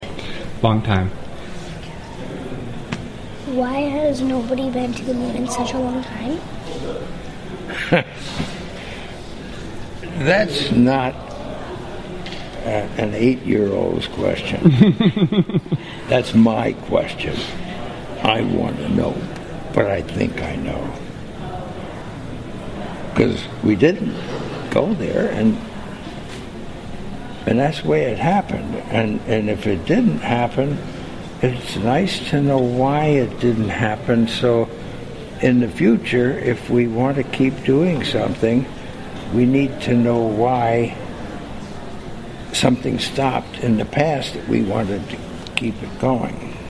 Also Buzz Aldrin Answers Interviewer Stating that We Didn't Go to the Moon